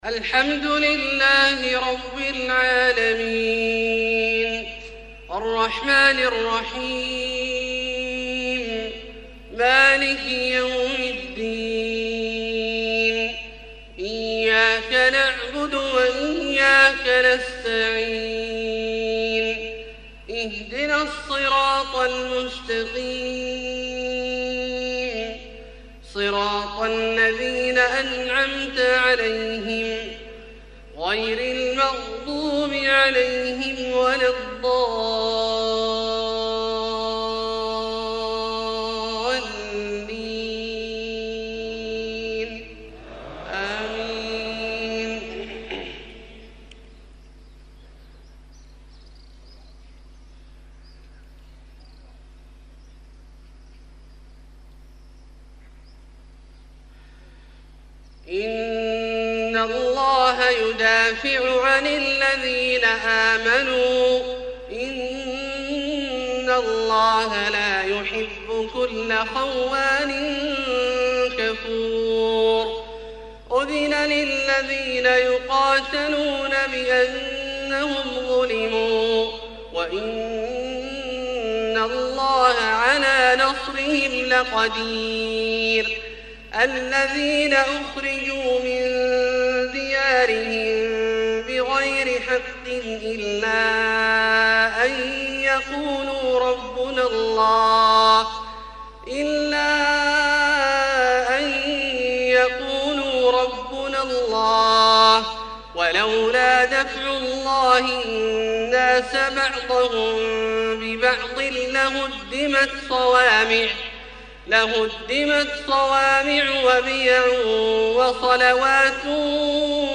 صلاة المغرب 7-5-1432 من سورة الحج{38-47} > ١٤٣٢ هـ > الفروض - تلاوات عبدالله الجهني